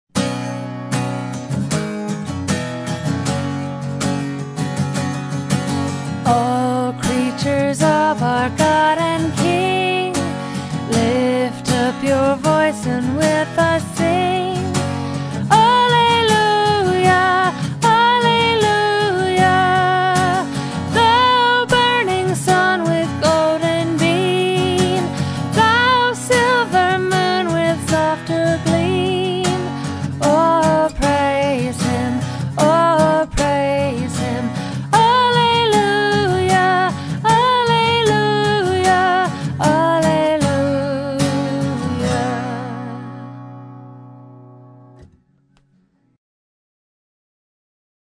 Música en formato Midi: